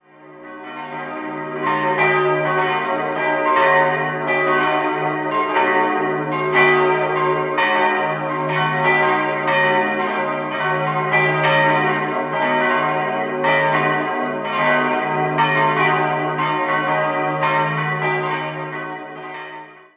4-stimmiges Geläute: es'-ges'-b'-des'' Glocke 1 es' 1.190 kg 1957 Rudolf Perner, Passau Glocke 2 ges' 600 kg 1704 Schelchshorn, Regensburg Glocke 3 b' 310 kg 1763 Kissner, Stadtamhof Glocke 4 des'' 200 kg 1704 Schelchshorn, Regensburg